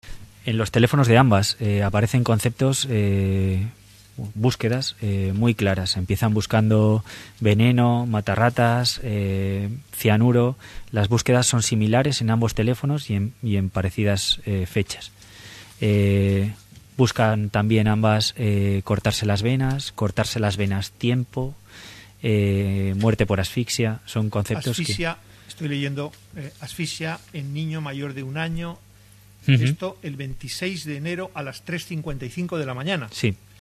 JUICIO-INVESTIGADOR-TELEFONOS_.mp3